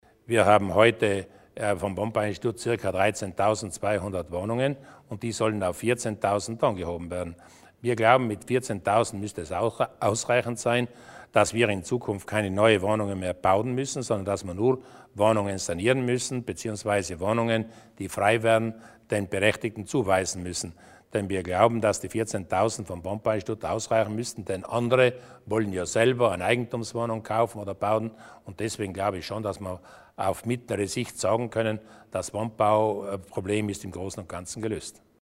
Landeshauptmann Durnwalder zu den Investitionen in den geförderten Wohnbau